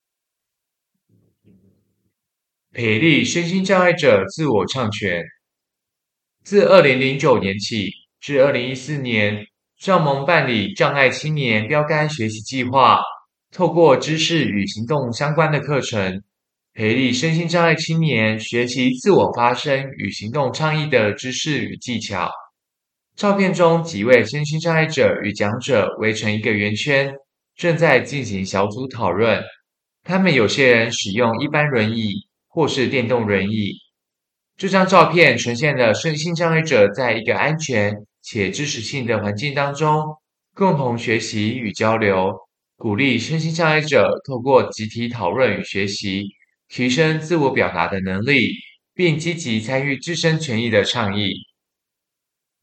所有文字內容會轉成語音檔，每幅作品旁都附有語音 QR-Code，讓視障朋友或不便閱讀的民眾能掃描聆聽，用「聽」的方式來欣賞作品。